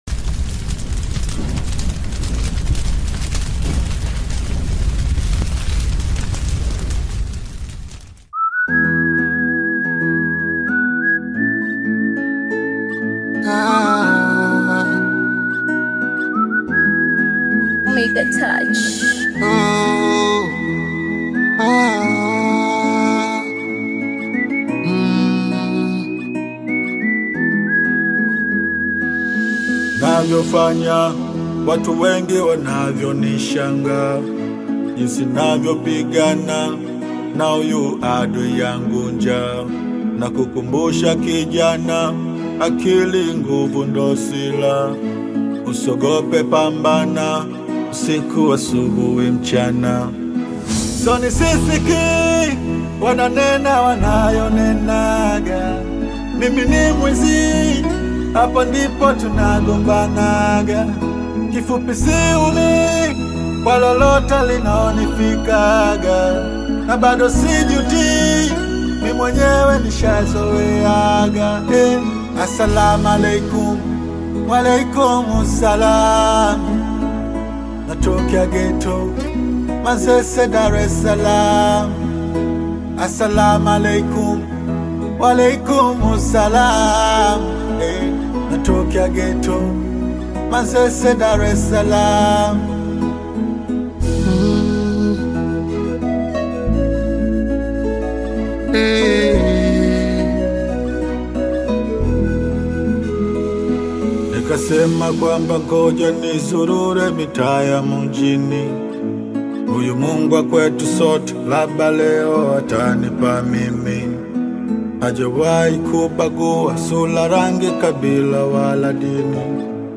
Tanzanian Bongo Flava artist, singer, and songwriter
Bongo Flava